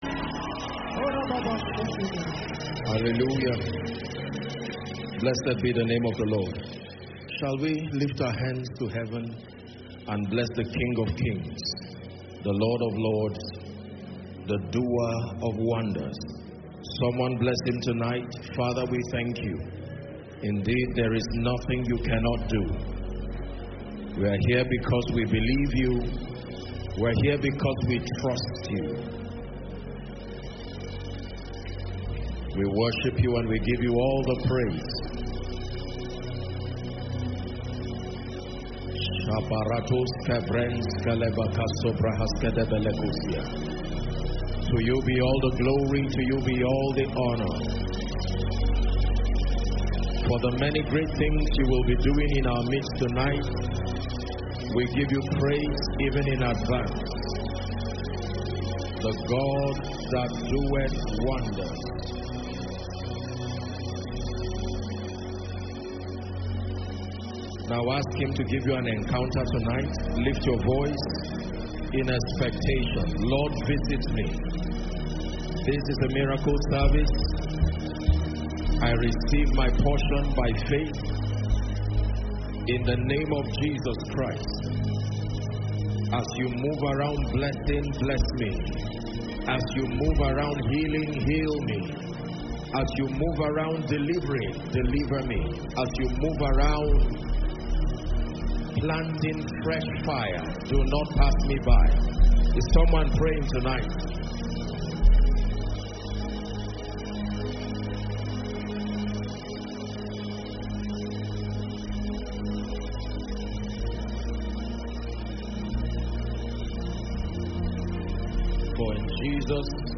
Koinonia Miracle service is a monthly programme organized by the Eternity Network International (ENI).